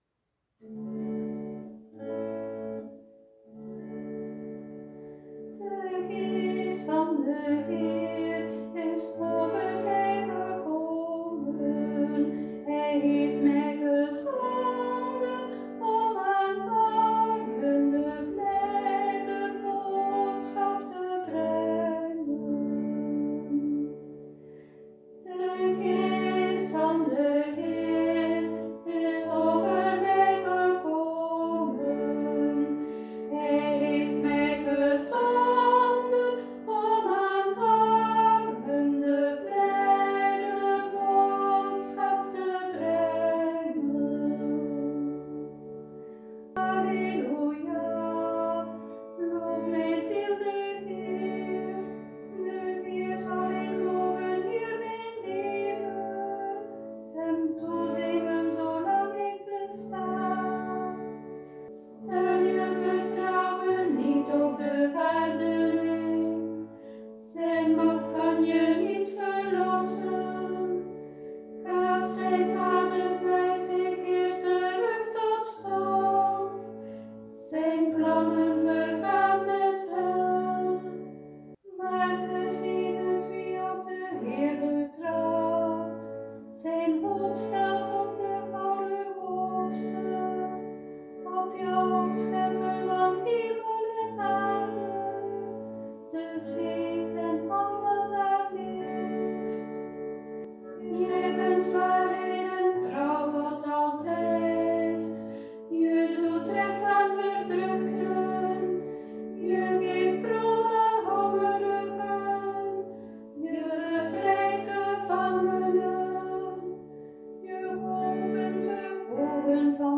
met citerbegeleiding Aanzet Onze ‘Psalm van de maand’ is overduidelijk een lofpsalm.